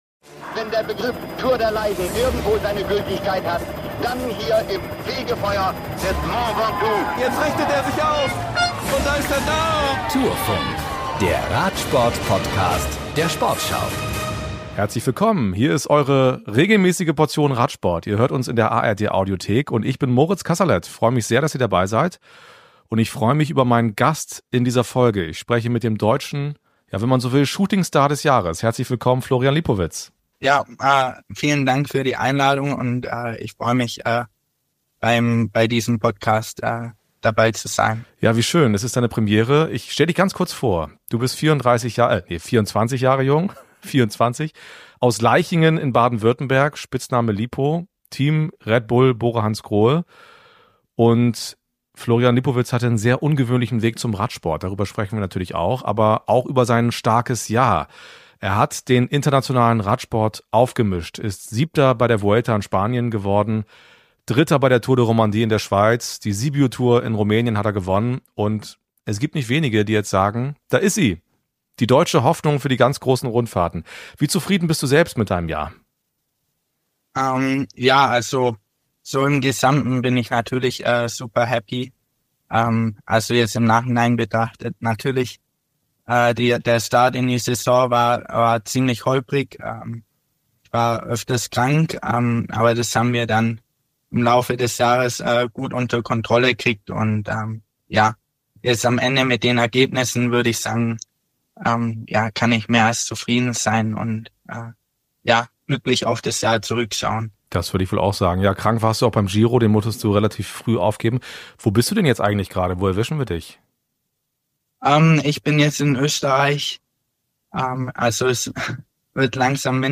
Er ist der deutsche Radsport-Shootingstar des Jahres: Florian Lipowitz spricht über seine Erfolge, seine großen Träume und über Ticks beim Essen und Einschlafen.